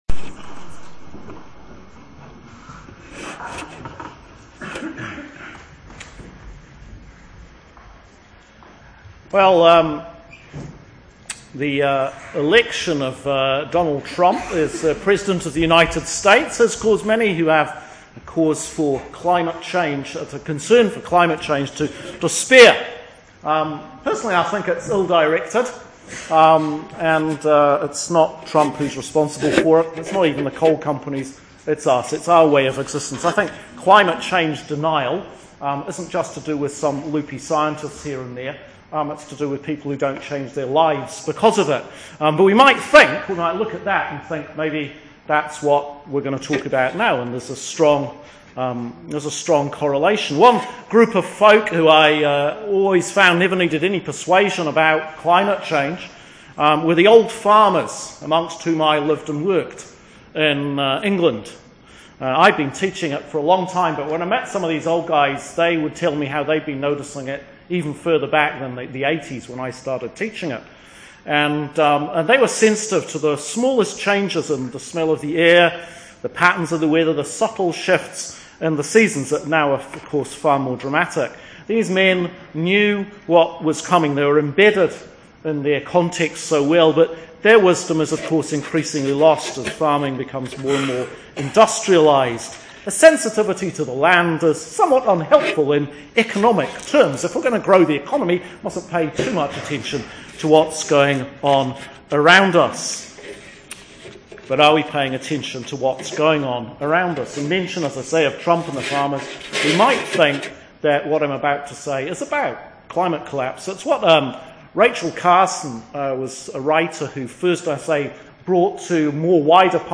‘The Quiet Apocalypse’ – Sermon for Advent Sunday, 2016. Year A